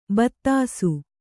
♪ battāsu